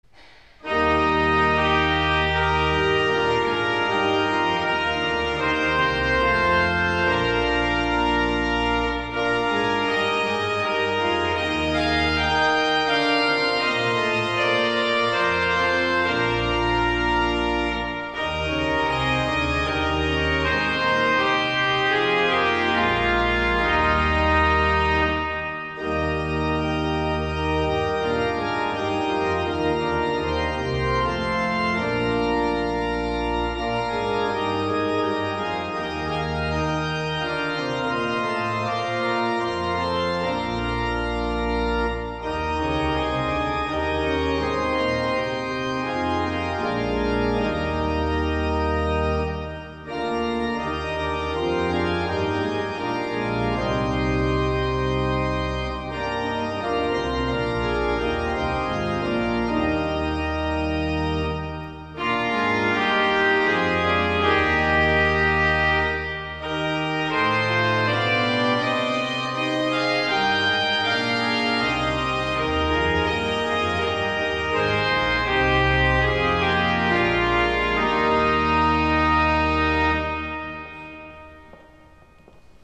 Fourth Sunday in Advent
Holy Eucharist
Organ - Johann Sebastian Bach, - Wachet auf